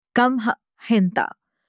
تلفظ: قَمْح (Qamh) مثال در جمله: یُنْتِجُ الْمِصْرِیُّونَ کَمِّیَاتٍ کَبِیرَةً مِنَ الْقَمْحِ .